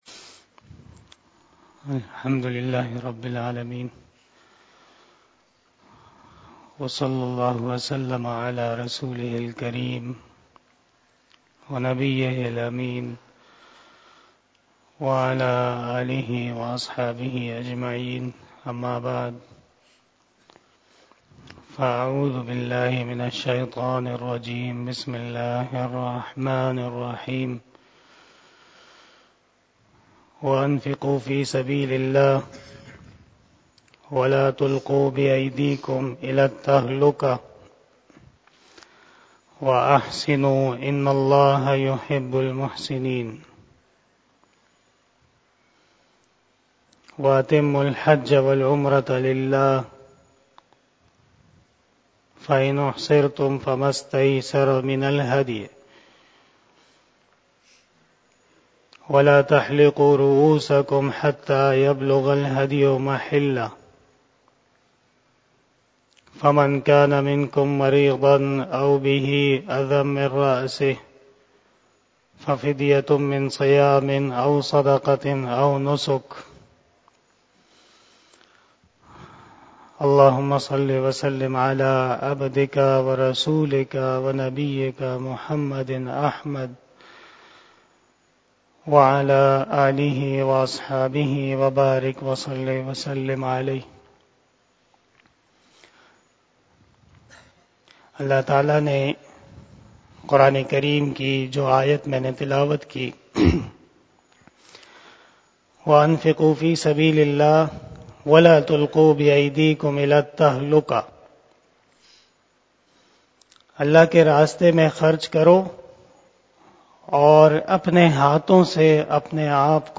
بیان شب جمعۃ المبارک29 دسمبر2022 بمطابق 06 جمادی الثانی 1444ھ